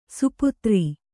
♪ suputri